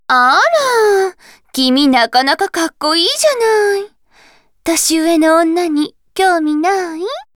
CV ：小清水亚美